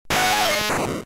Cri d'Aspicot K.O. dans Pokémon Diamant et Perle.